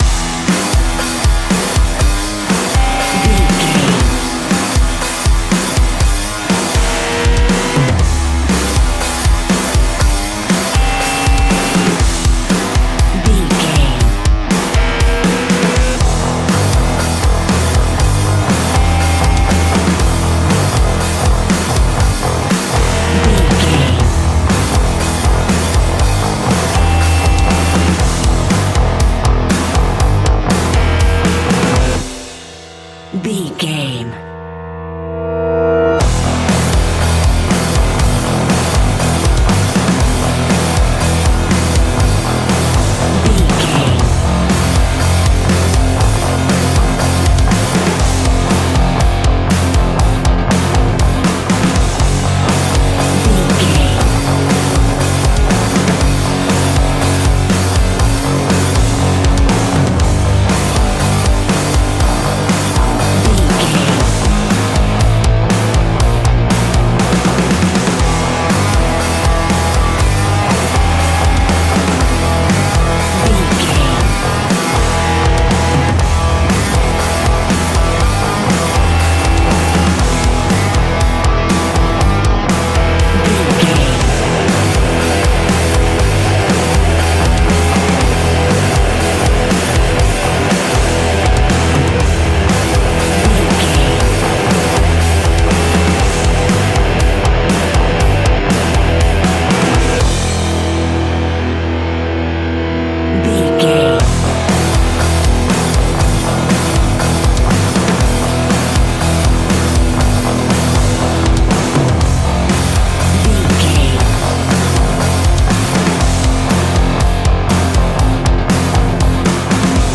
Ionian/Major
E♭
hard rock
guitars
heavy metal
instrumentals